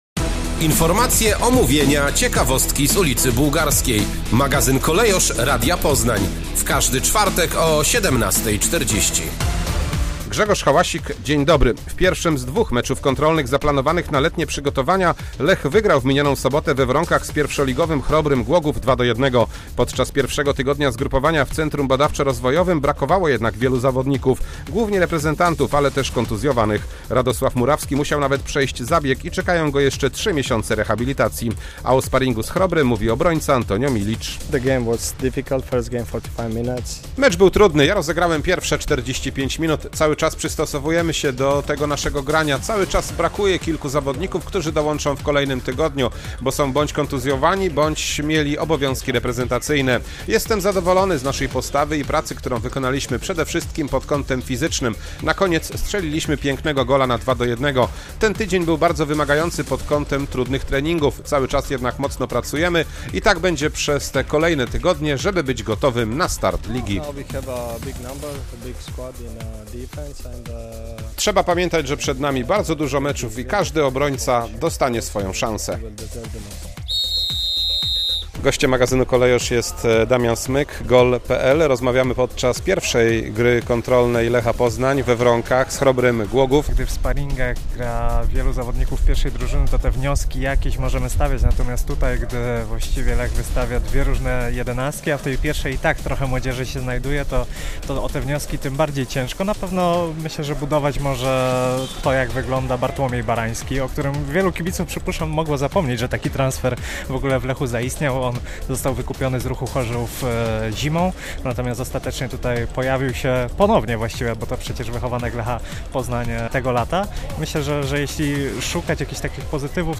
W magazynie Kolejorz rarpot z prtzygotowań do nowego sezonu, rozmowy z ekspertam i nowym zawodnikiem Lecha.